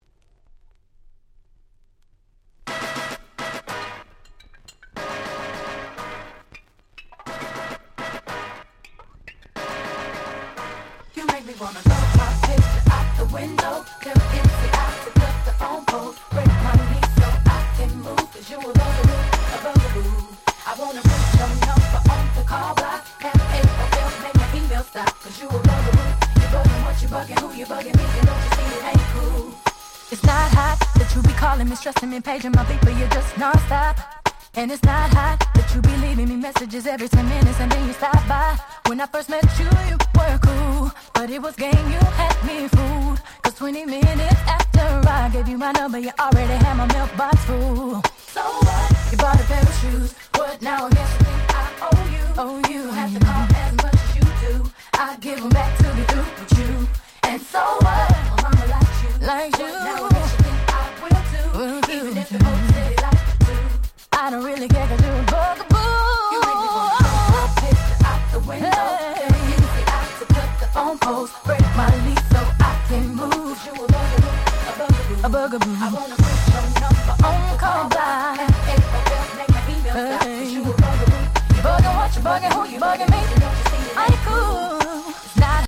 99' Super Hit R&B !!